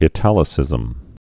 (ĭ-tălĭ-sĭzəm)